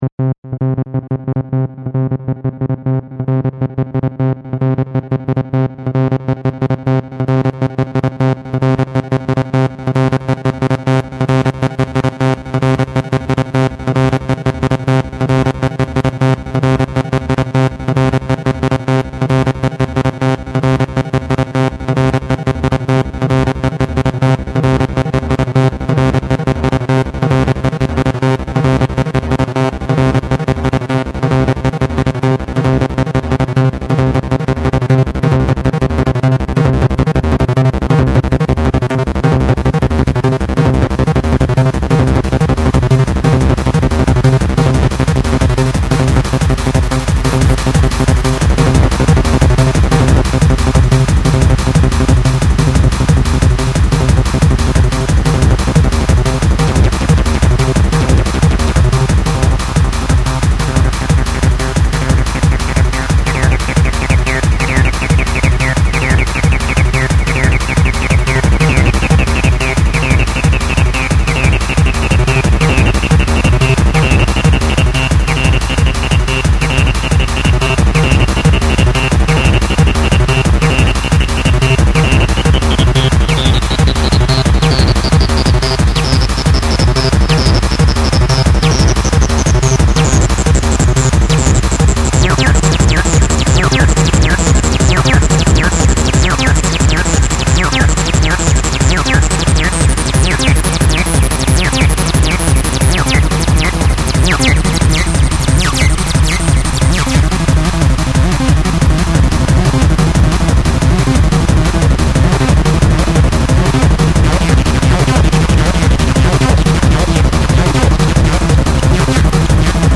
Rave Psychedelic
165bpm